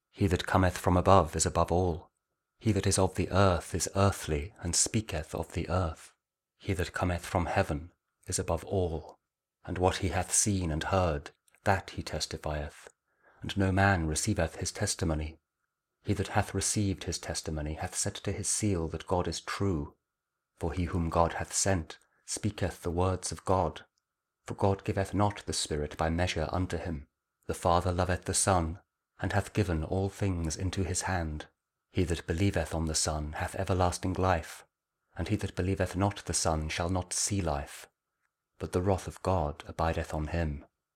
John 3: 31-36 Audio Bible KJV | King James Audio Bible | Daily Verses